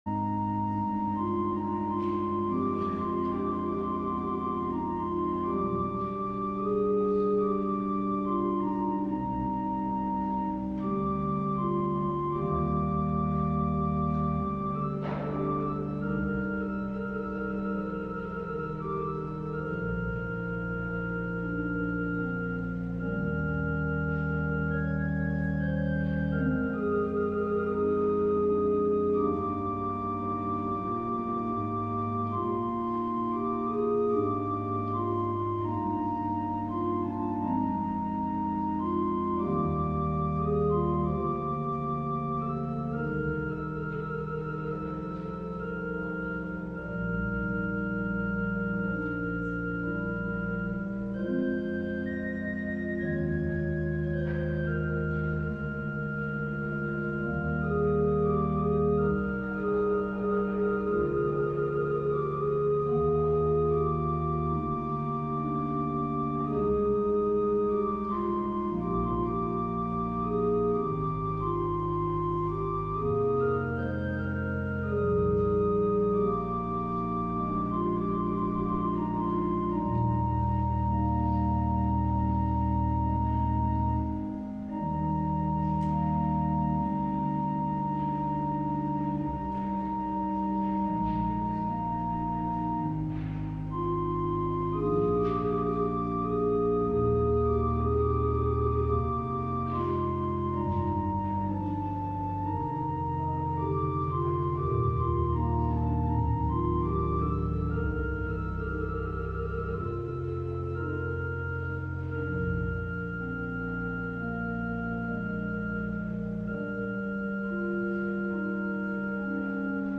LIVE Morning Service - Cross Words: Contrition